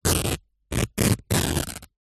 Звук рвущихся хлопковых трусов